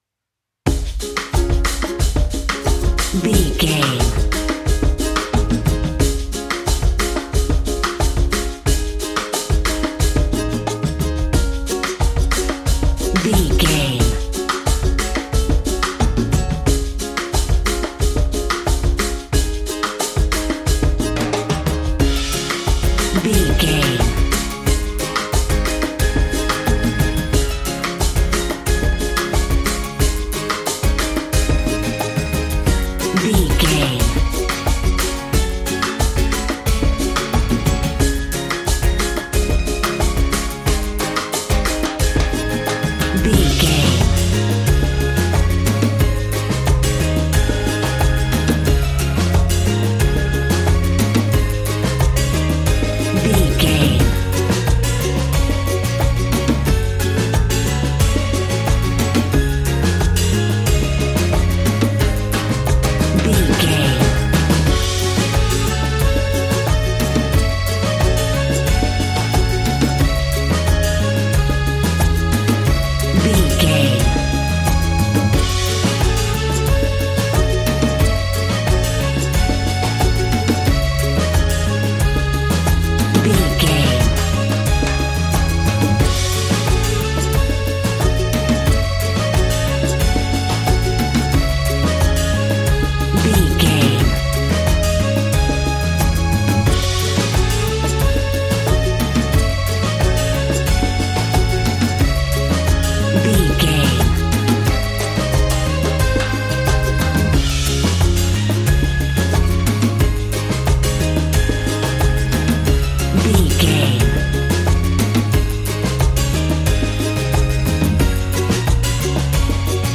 Aeolian/Minor
D♭
cheerful/happy
mellow
drums
electric guitar
percussion
horns
electric organ